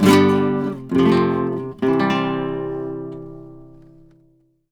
Index of /90_sSampleCDs/USB Soundscan vol.59 - Spanish And Gypsy Traditions [AKAI] 1CD/Partition B/04-70C RUMBA